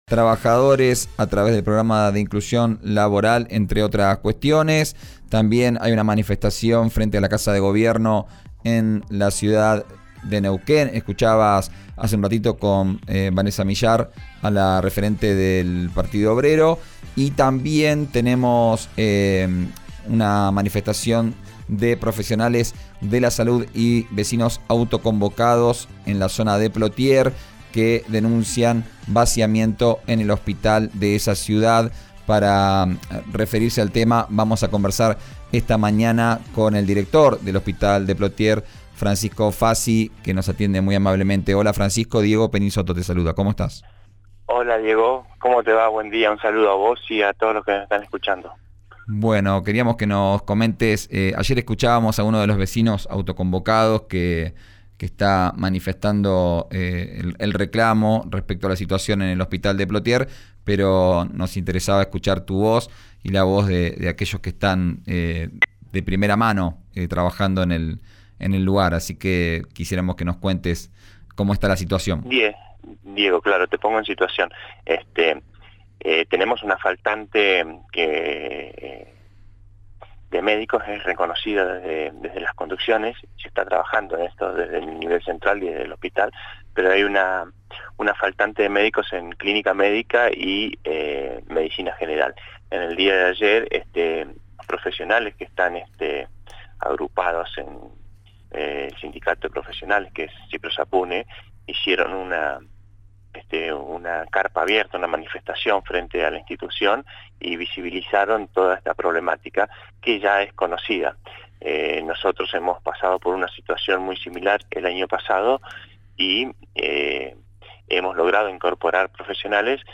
declaraciones en “Vos al aire” de RÍO NEGRO RADIO